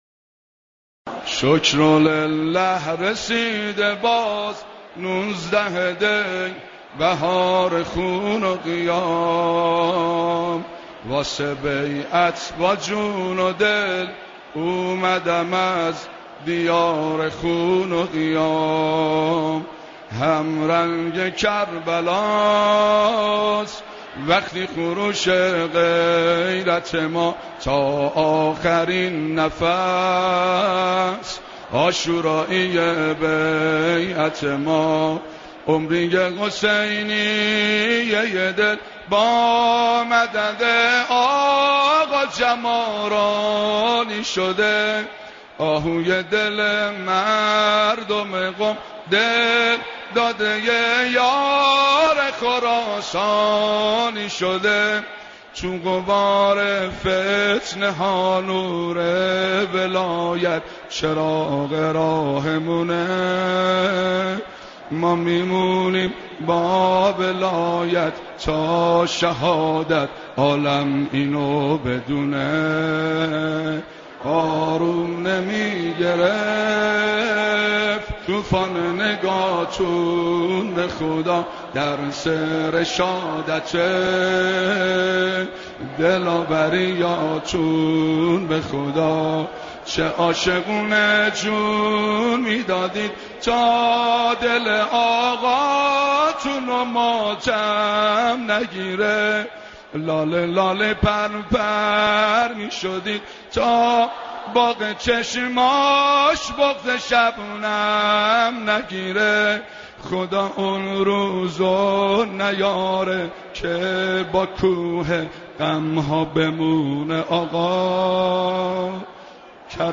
مداحی
هم خوانی زیبای مردم قم
در دیدار بارهبر معظم انقلاب